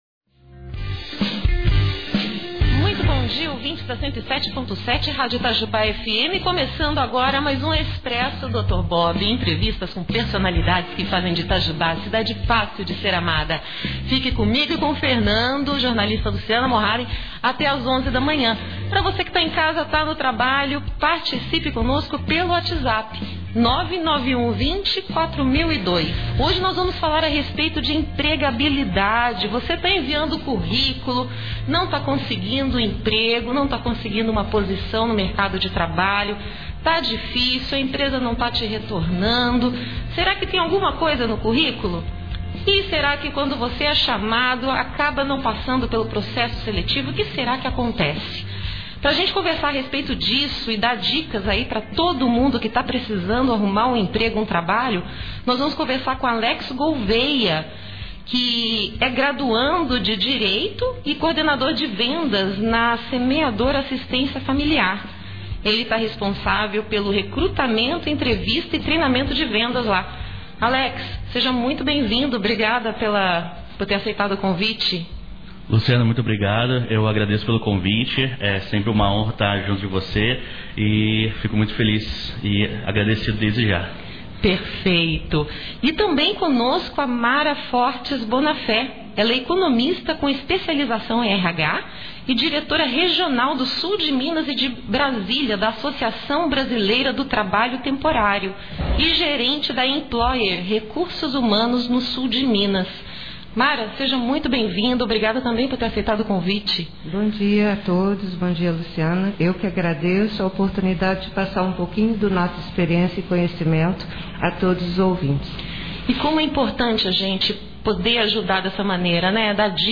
comentou sobre empregabilidade e deu dicas em entrevista à Rádio Itajubá AM. Aperte o play e acompanhe essa conversa que agrega à candidatos e profissionais de RH.